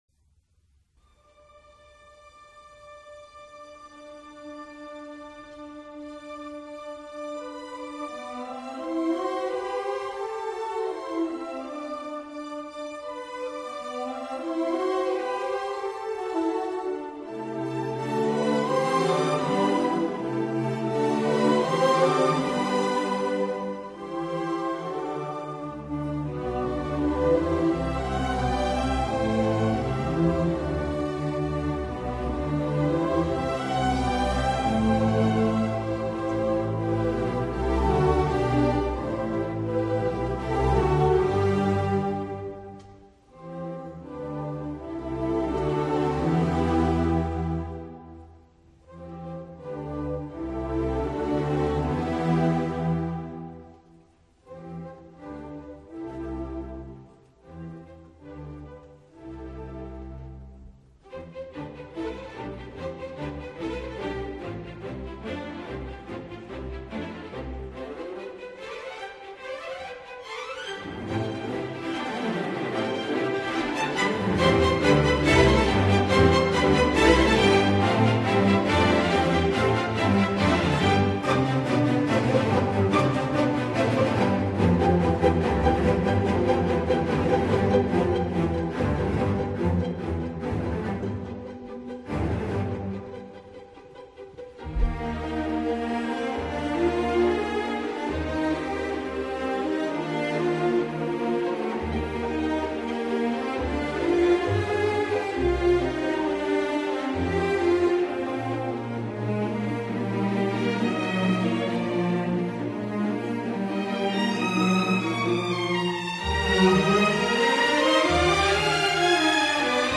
Ensemble de cordes